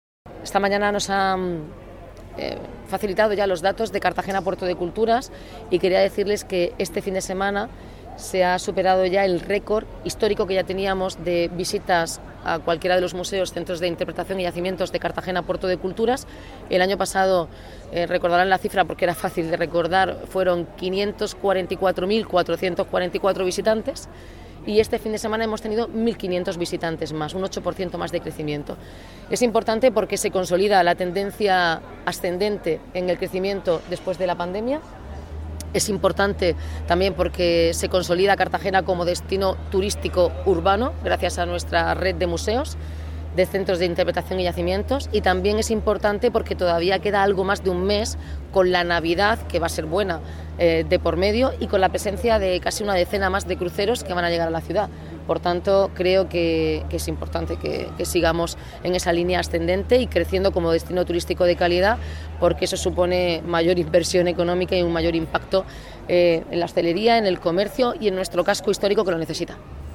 Enlace a Declaraciones de la alcaldesa, Noelia Arroyo, sobre visitas Puerto de Culturas